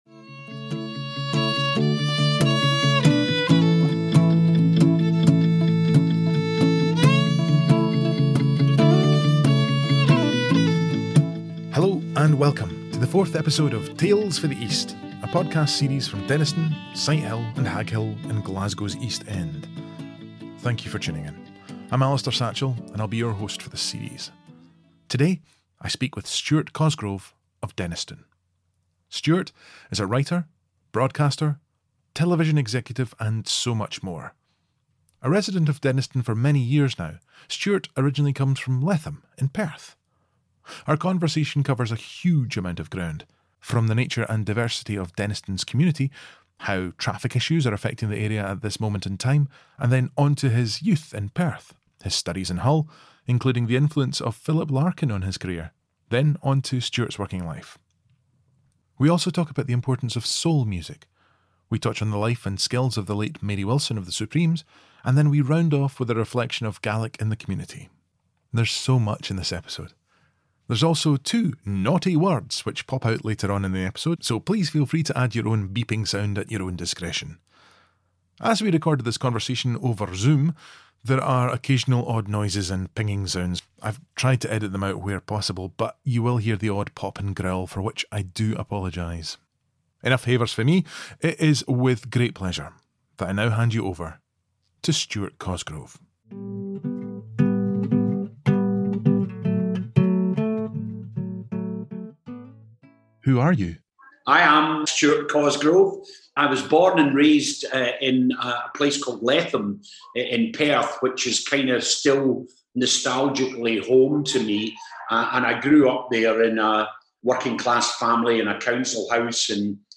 As we recorded this conversation over Zoom there are occasional odd noises and pinging sounds, I’ve tried to edit them out where possible, but you will hear the odd pop and growl for which I apologise.